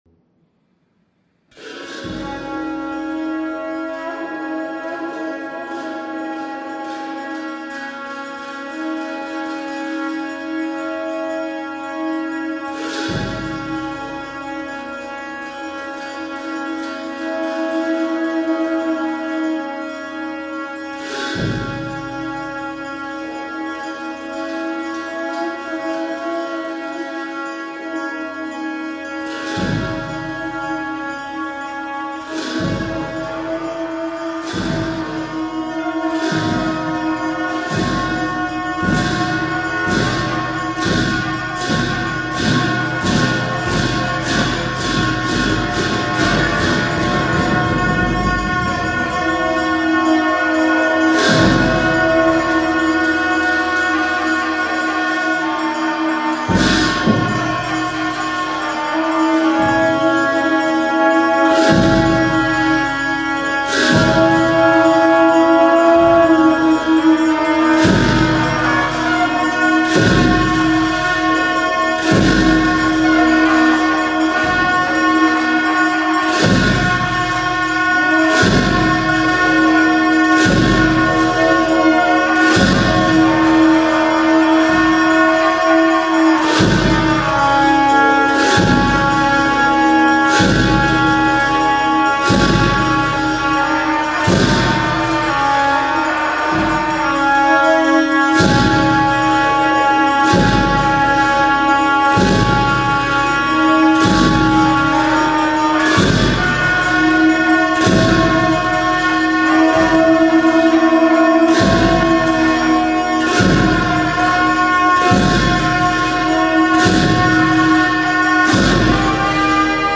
梵唄真言